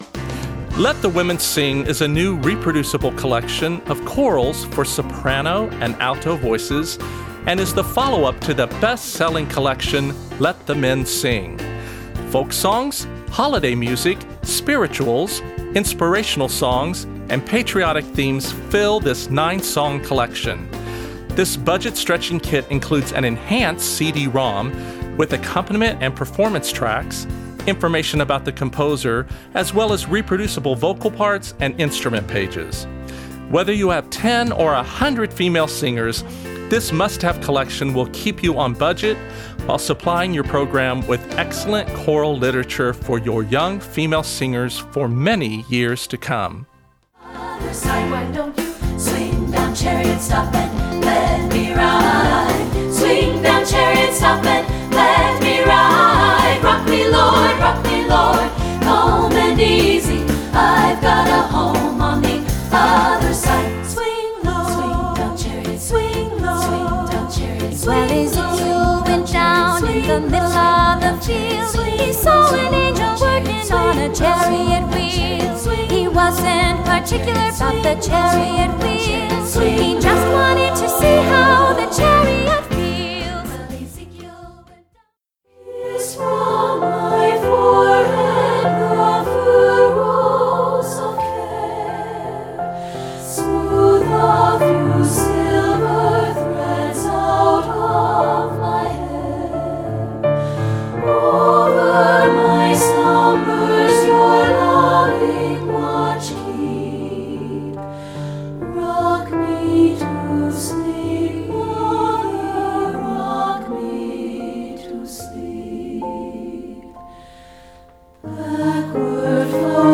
Voicing: SSA Collection